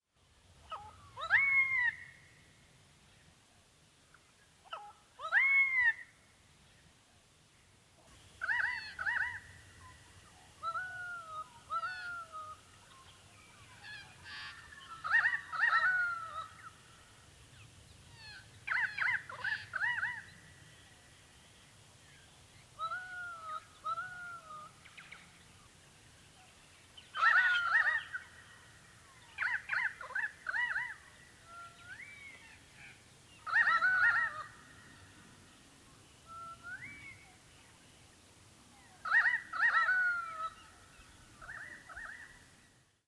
Our open paddocks near Strath Creek are dotted with many Pied Currawongs at present.
At times they can be a noisy lot, particularly when they come together in groups, with a diverse range of calls. On our frequent foggy days they can sound quite eerie and mournful, at other times rather plaintive. Click below to hear a selection of their calls.
pied-currawongs.mp3